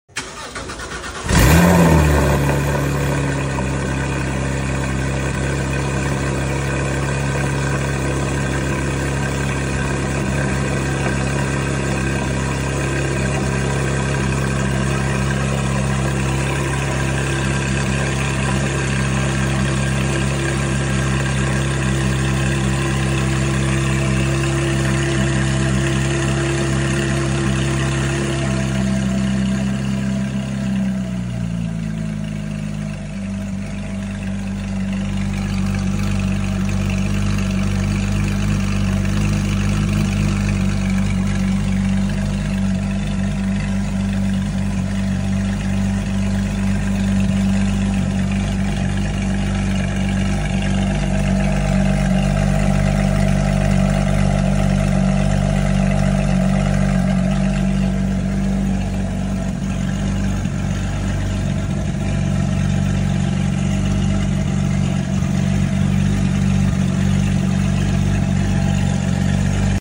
🔥 Porsche 911 GT3 RS – Cold Start Symphony 🔊 4.0L naturally aspirated flat-six roaring to life with surgical precision. No turbos, no tricks — just raw Porsche Motorsport DNA.